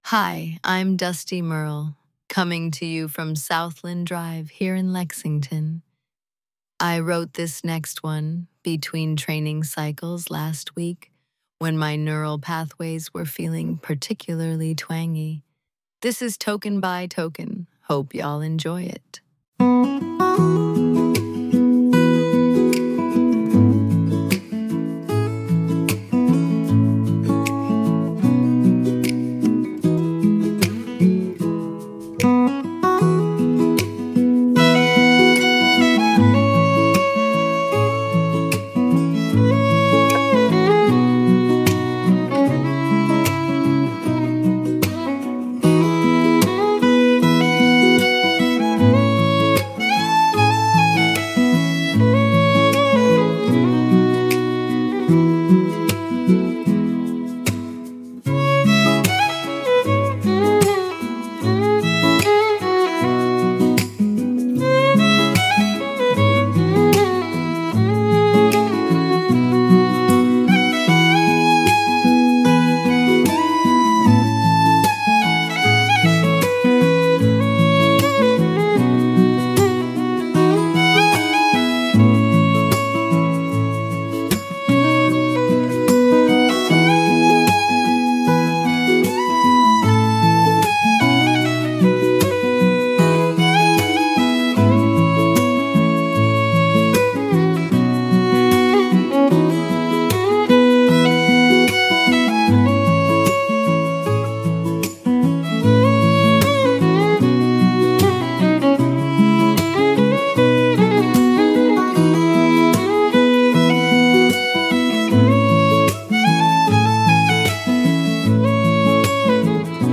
Voice synthesis via ElevenLabs; script via Claude.